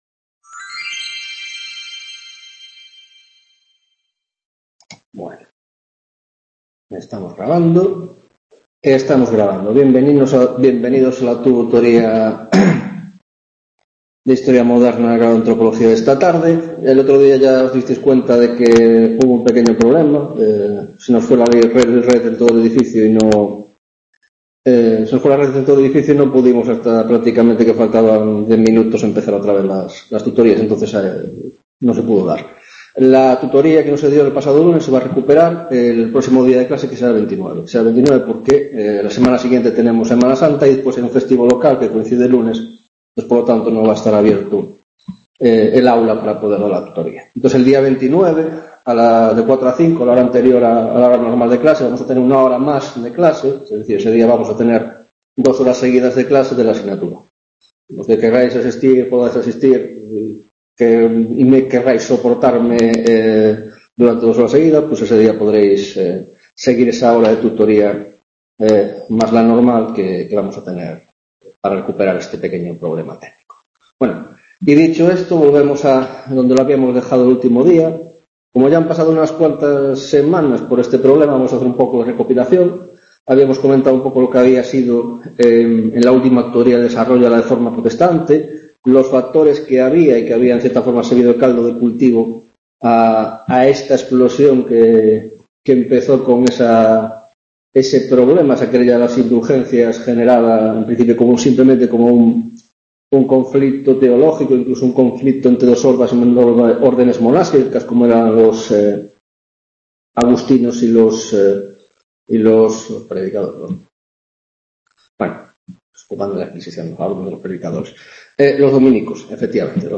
7ª tutoría de Historia Moderna, Grado de Antropología - Reforma Protestante